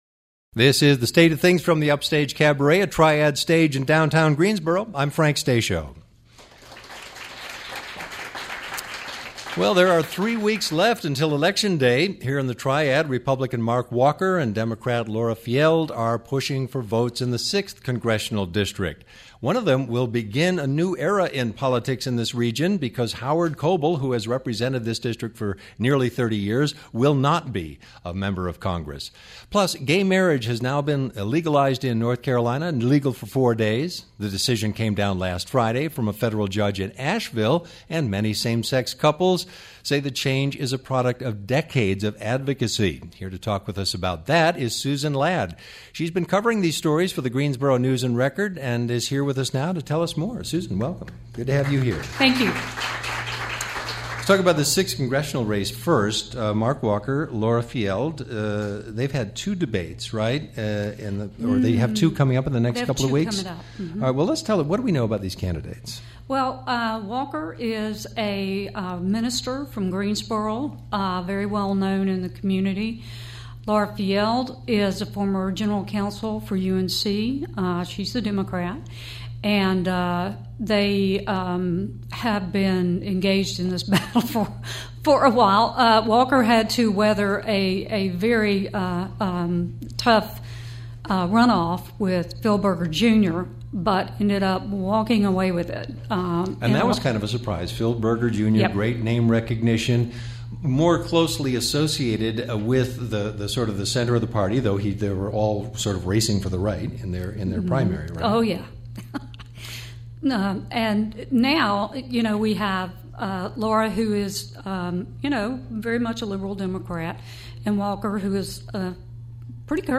A triad news update